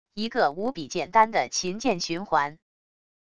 一个无比简单的琴键循环wav音频